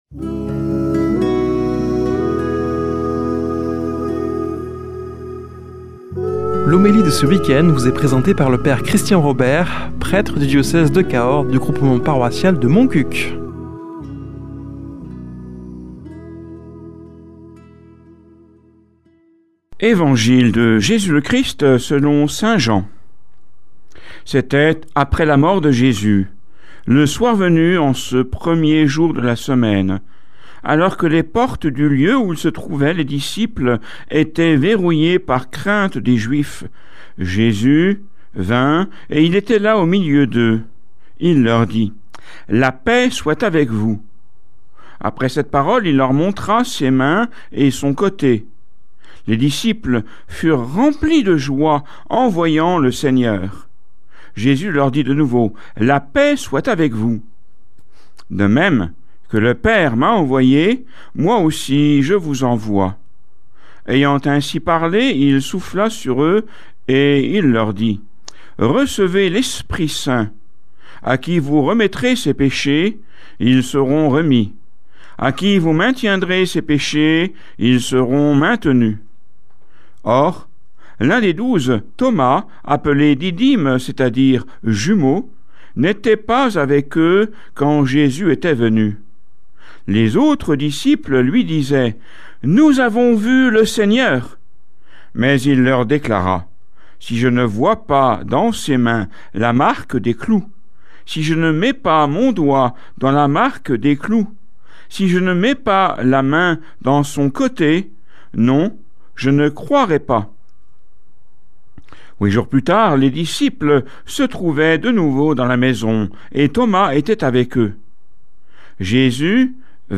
Homélie du 27 avr.